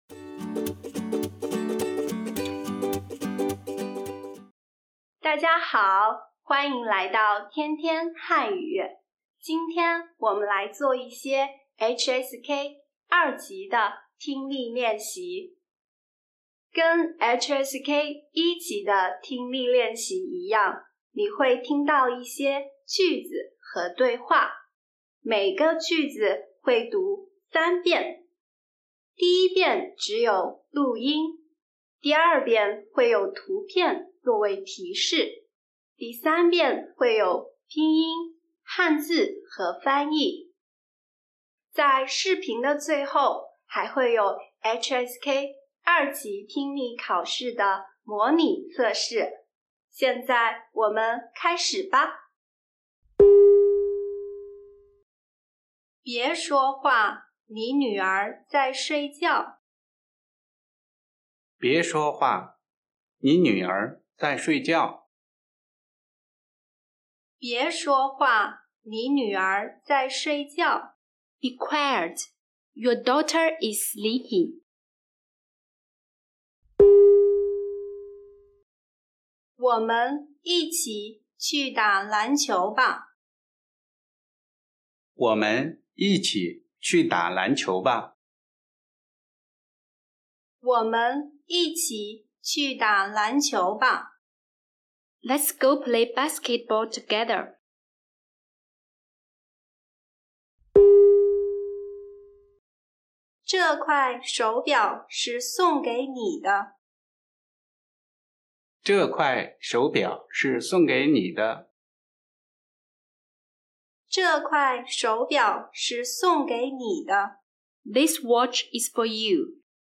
HSK 2 Listening Test – Chinese Listening Practice for Beginners